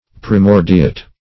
\Pri*mor"di*ate\